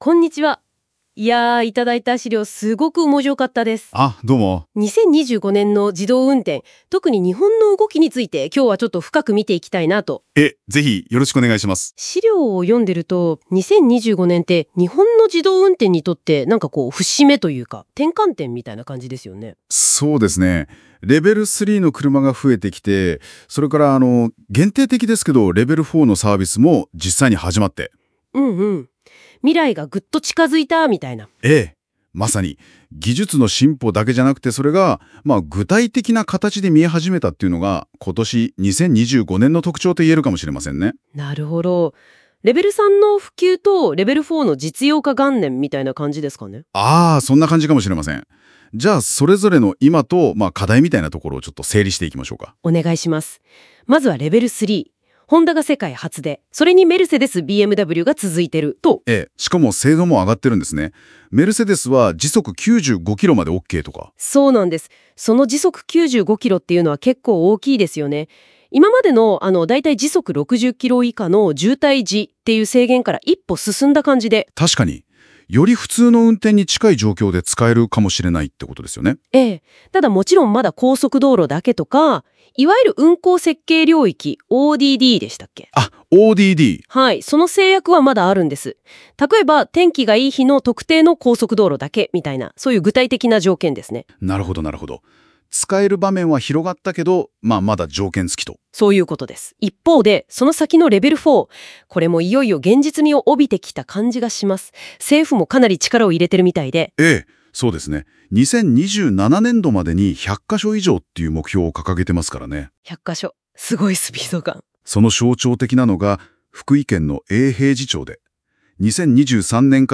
今回の記事はAIラジオでお聞きになることもできます。